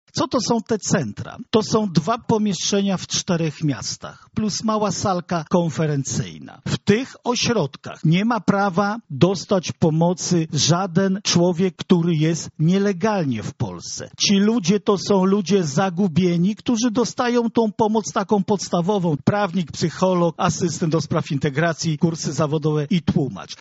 Źródło: transmisja z konferencji na Facebooku
O Centrach Integracji Cudzoziemców mówi Marszałek Województwa Lubelskiego Jarosław Stawiarski.
konferencja.mp3